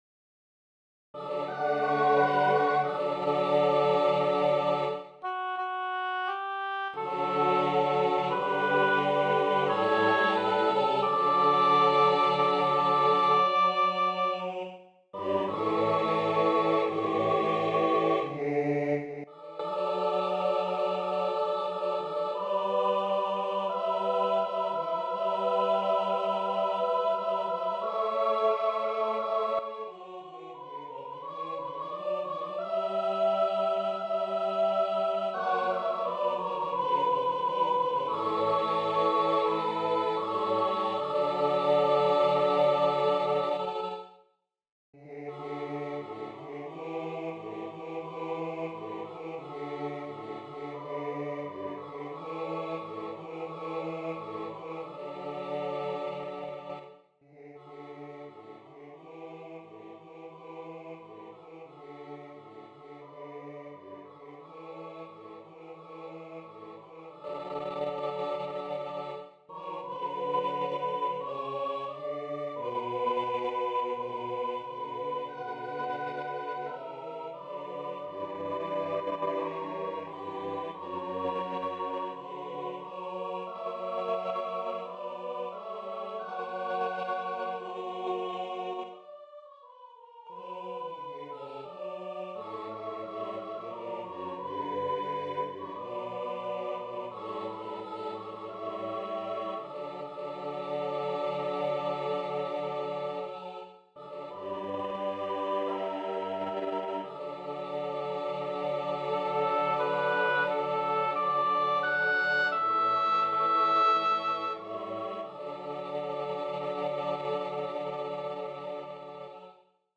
Мужской хор (на русском и украинском языках)